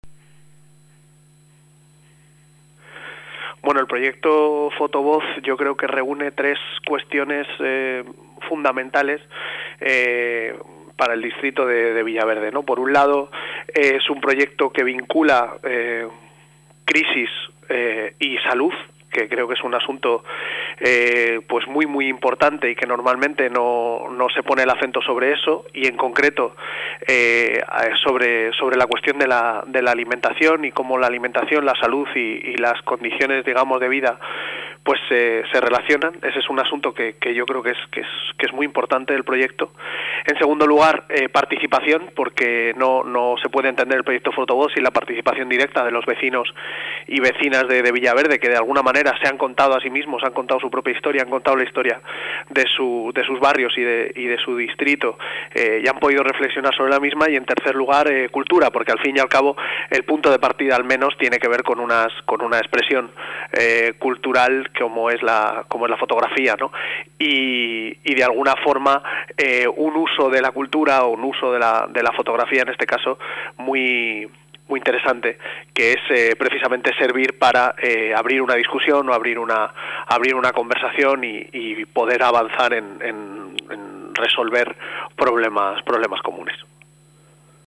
Nueva ventana:Sonido- Guillermo Zapata habla sobre el proyecto 'Fotovoz Villaverde